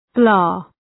Shkrimi fonetik {blɑ:}